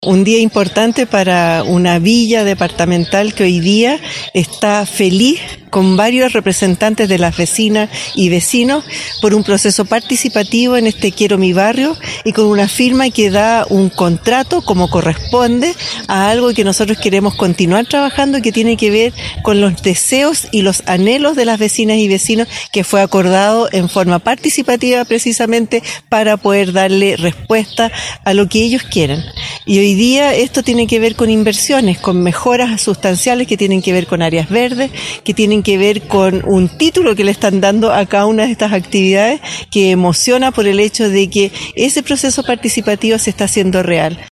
La Alcaldesa Carmen Castillo lo calificó como un «día histórico».